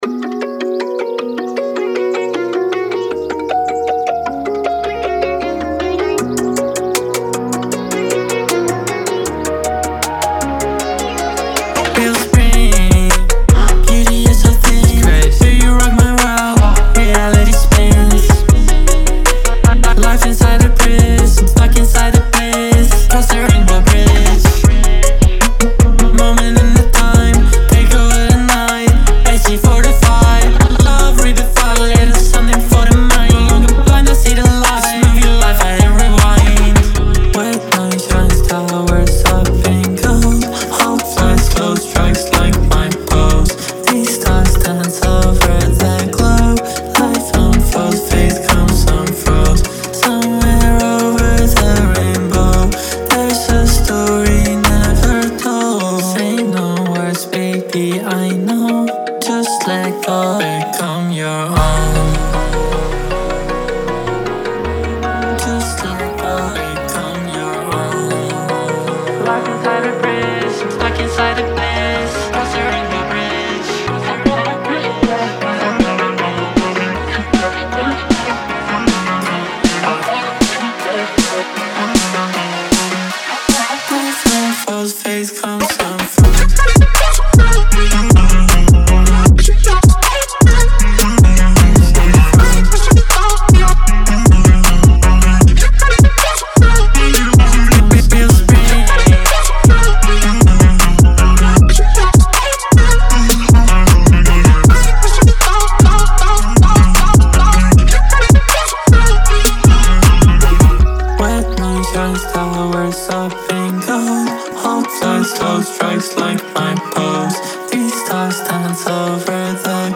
BPM156-156
Audio QualityPerfect (High Quality)
Trap EDM song for StepMania, ITGmania, Project Outfox
Full Length Song (not arcade length cut)